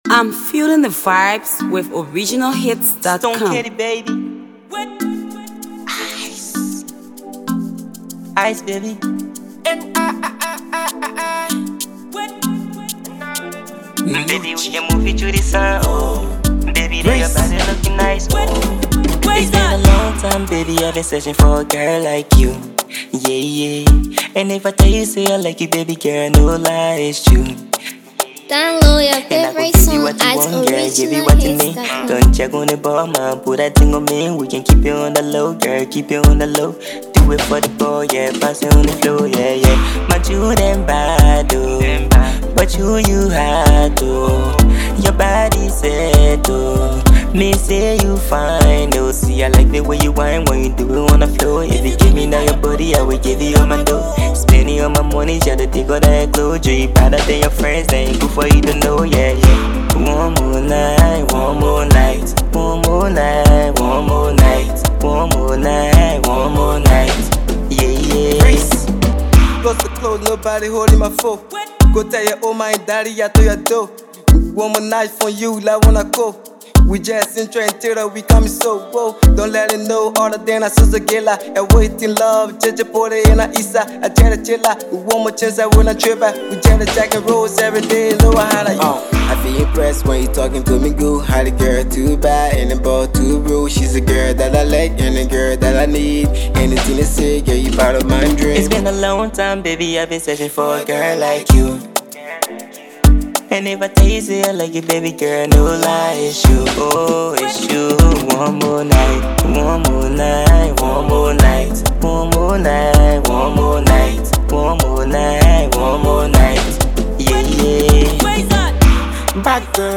A classical banger that will humble you!!!